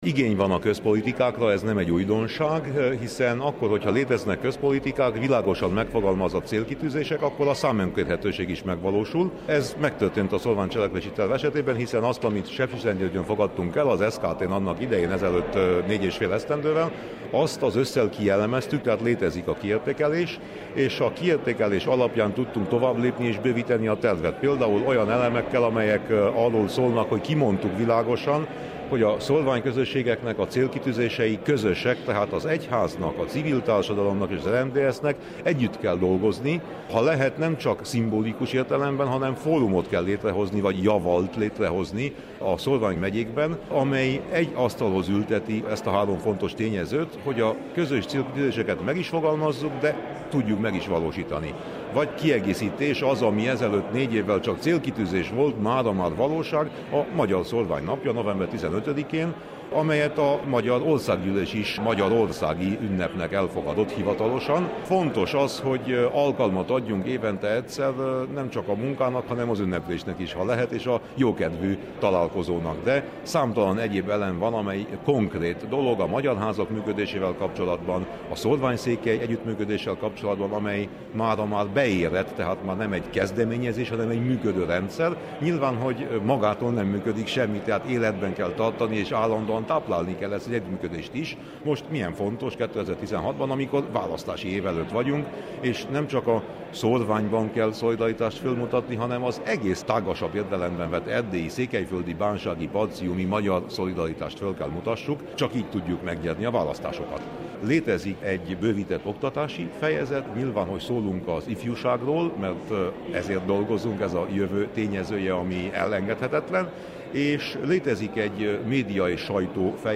Kiegészített Szórvány cselekvési tervet fogadott el az RMDSZ Szövetségi Képviselők Tanácsa a hétvégén Marosvásárhelyen. A tervet kezdeményező Winkler Gyula RMDSZ-es európai parlamenti képviselőt kérdeztem az újdonságokról.